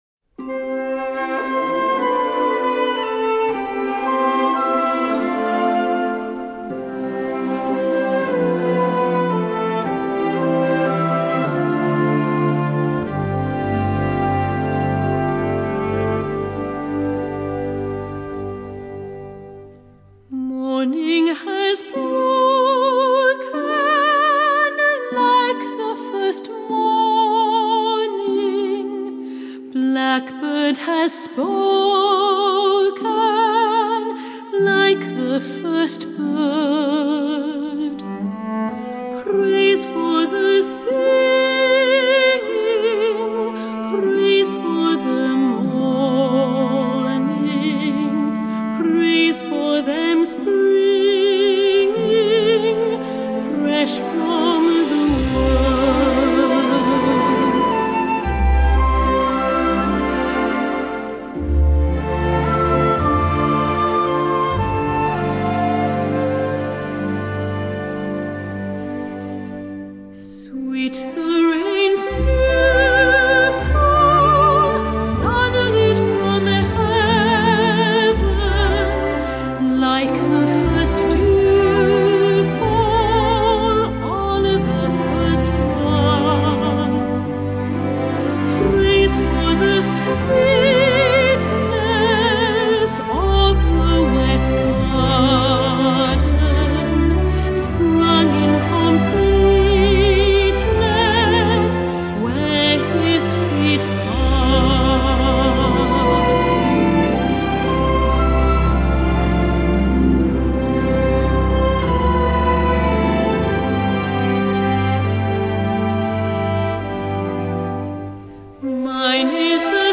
Crossover